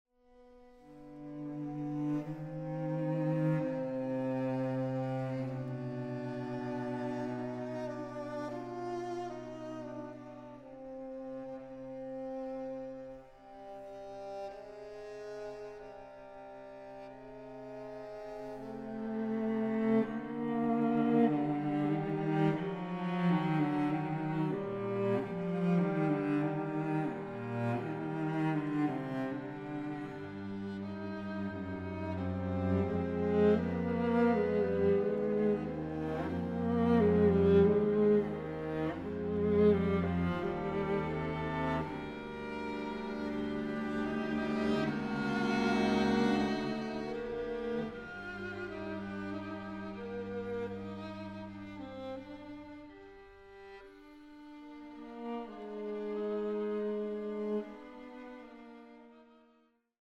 String Quartet No. 8 in C minor, Op. 110